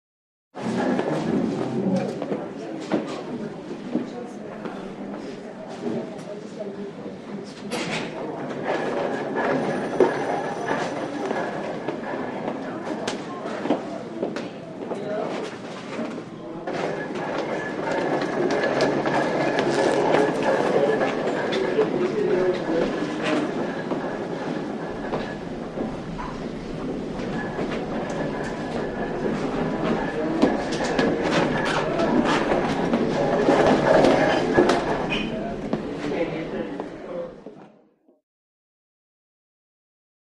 Hospital; Hallway Ambience; Interior Hospital Hallway Ambience; Stretcher Rolls / Footsteps / Phone Rings / Computer Printers / Walla, Medium Perspective.